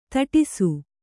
♪ taṭisu